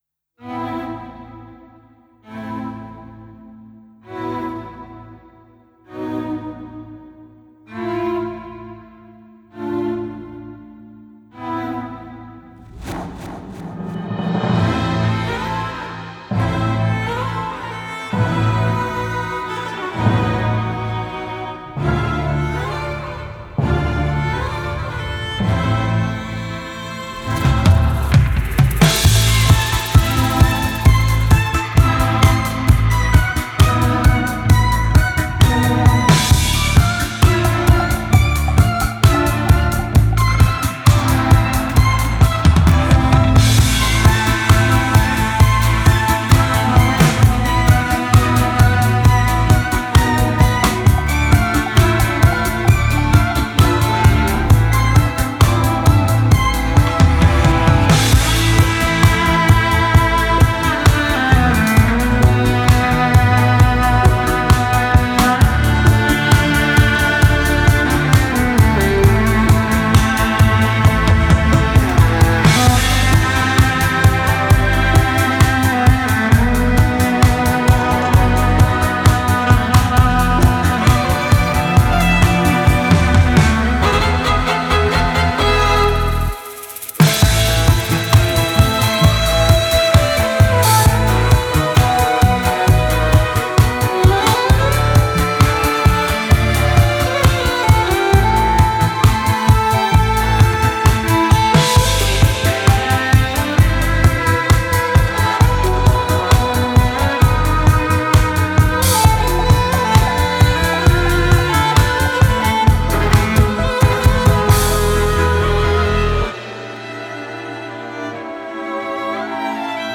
электронный струнный квартет из Лондона, Великобритания
Genre: Electronic, Classical